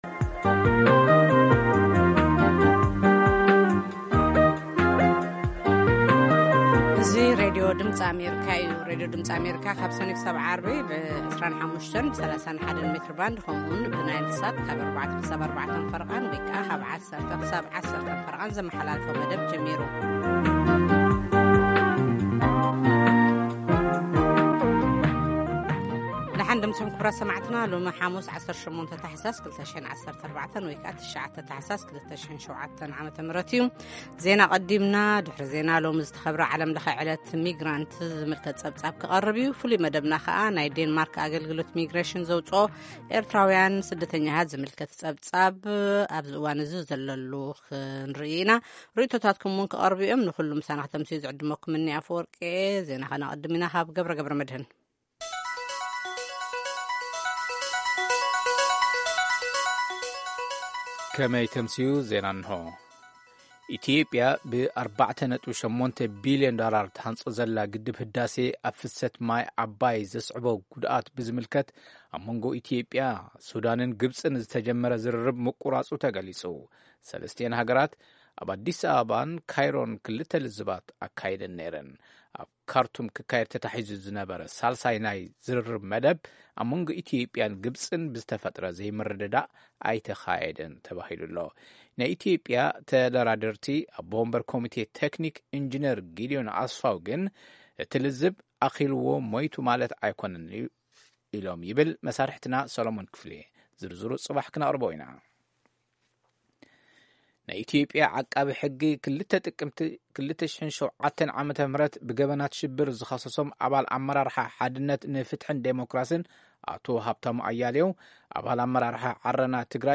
Tigrigna News 1900